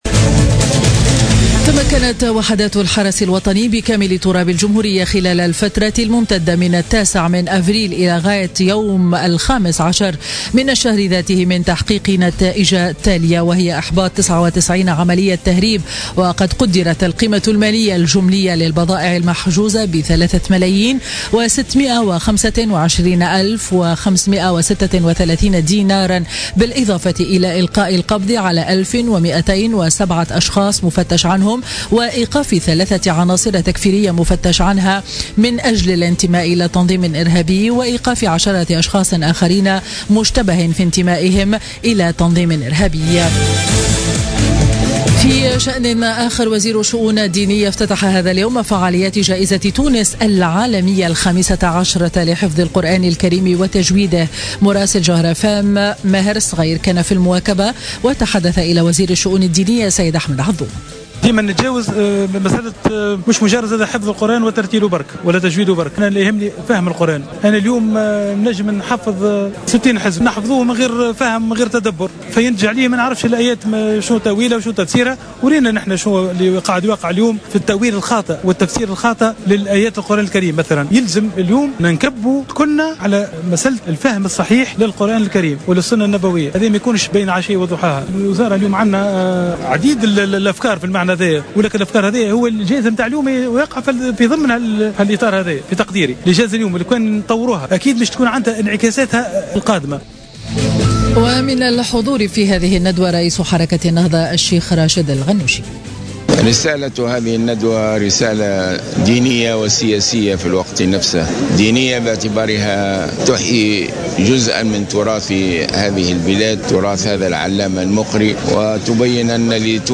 نشرة أخبار منتصف النهار ليوم الإثنين 17 أفريل 2017